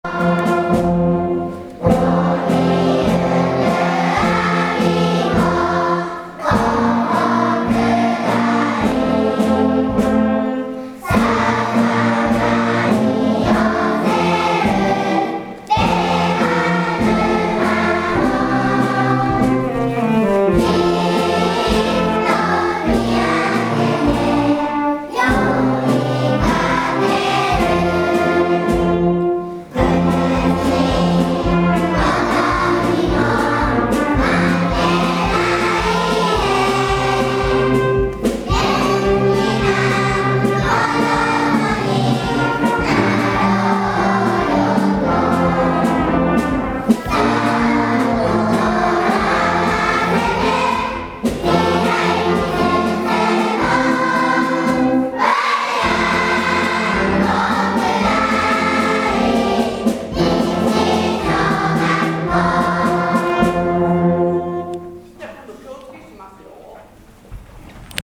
４月２８日（金），５月の全校朝礼を開催しました。
１年生も入っての校歌斉唱